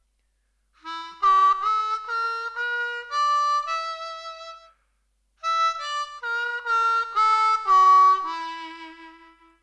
3rd-position-blues-scale-low-octave-D-harp.mp3